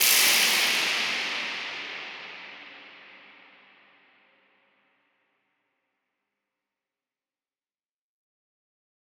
Index of /musicradar/shimmer-and-sparkle-samples/Filtered Noise Hits
SaS_NoiseFilterA-02.wav